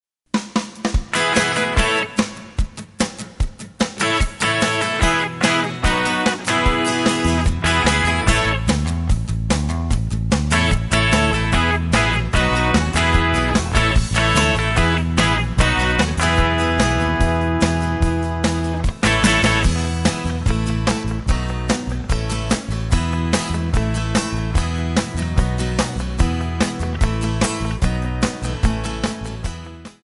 MPEG 1 Layer 3 (Stereo)
Backing track Karaoke
Country, 1990s